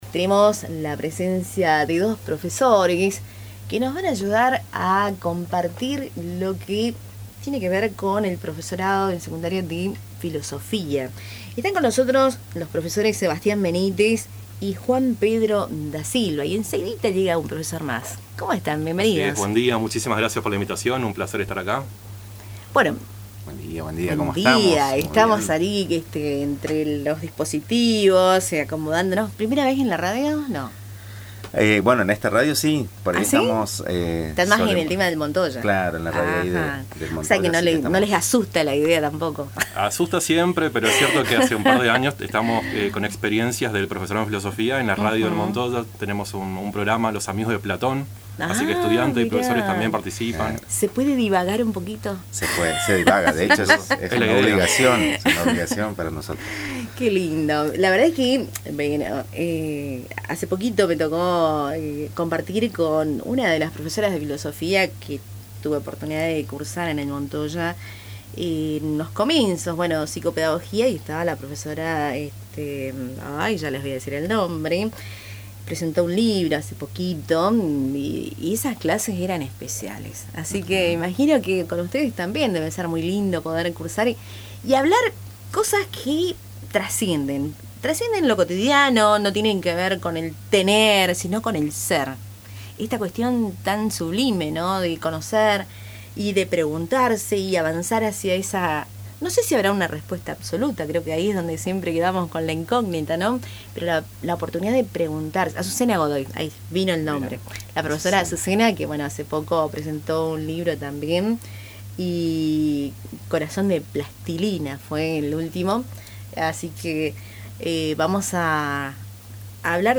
En los estudios de Radio Tupambaé